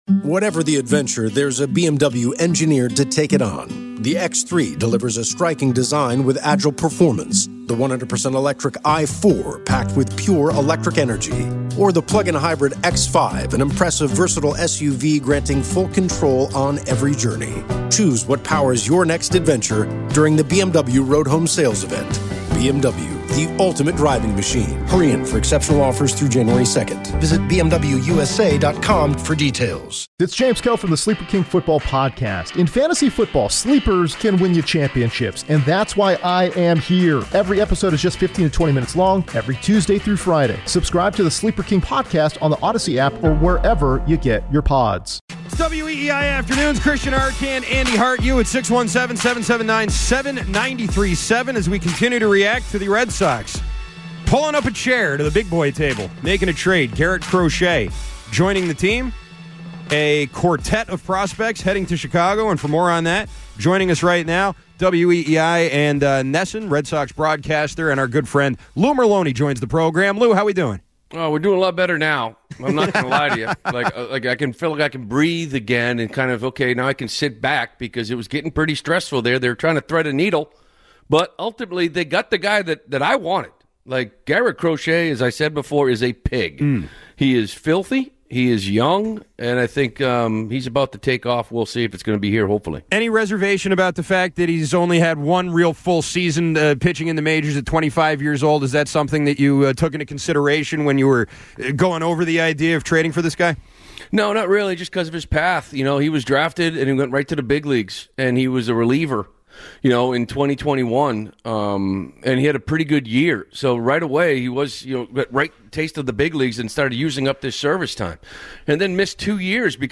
Plus, ESPN Senior NFL Insider Adam Schefter joined the show to share the latest information on the Belichick-to-UNC story.